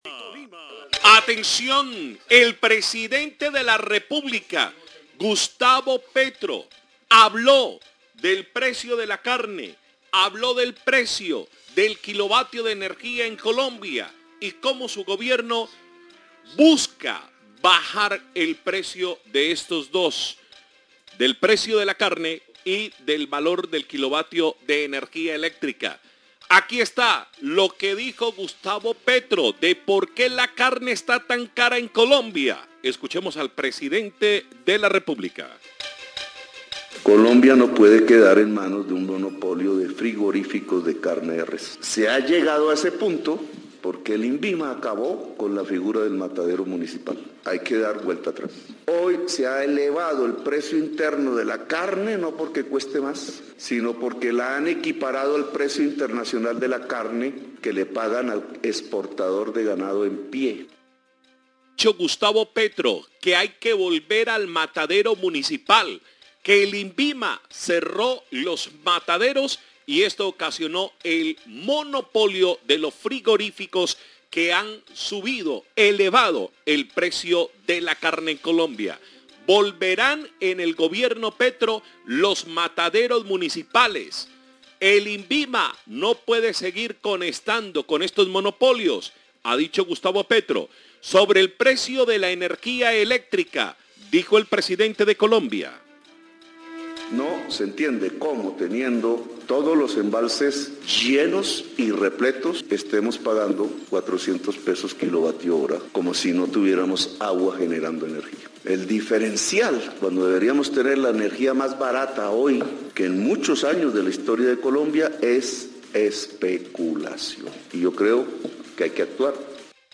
Radio
En una alocución hecha por el presidente Gustavo Petro, se refirió  a los altos costos de la carne de res en Colombia y el kilovatio de energía. Según el dirigente las represas están llenas y no hay razón para pagar los precios que actualmente están cobrando las empresas de energía